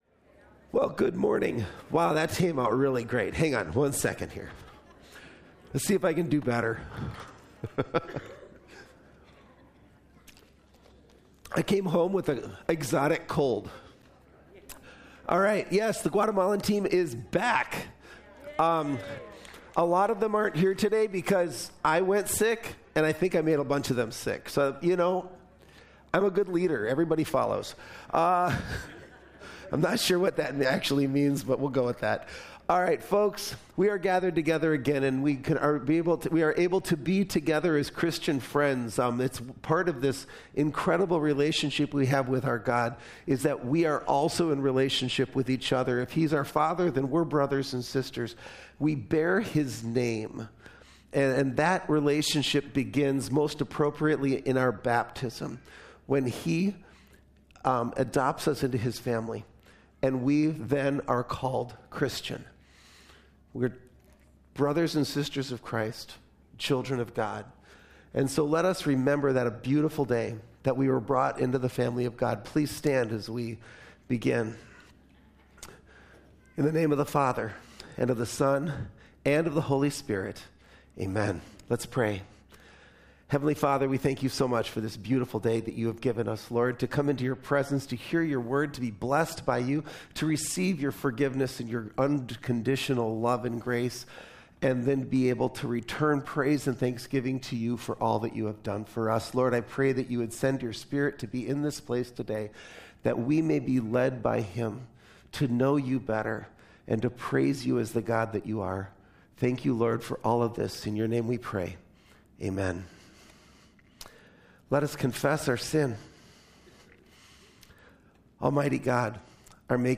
2026-February-8-Complete-Service.mp3